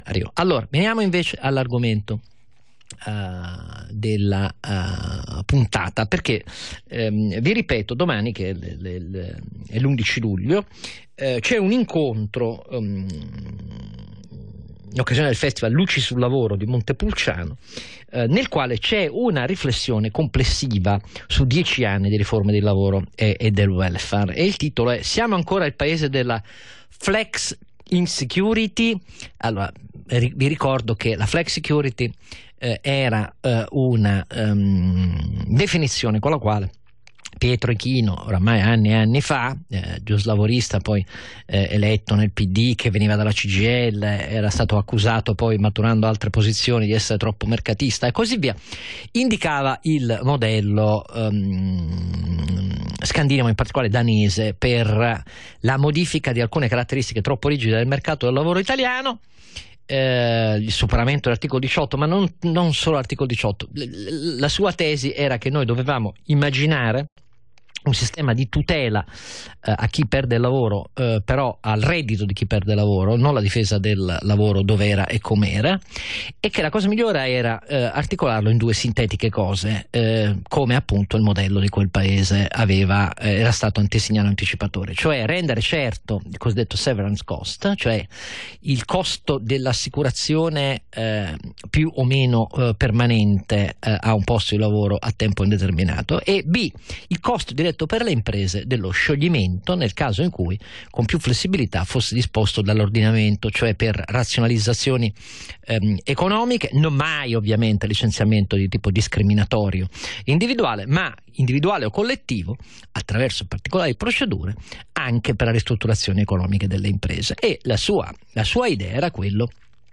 Intervento del Presidente Stefano Sacchi a "La versione di Oscar" su Radio24